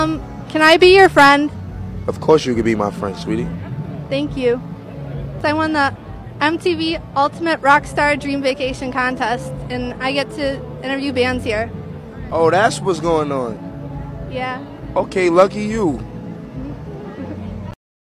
A$AP Rocky Most Akward Interview sound effects free download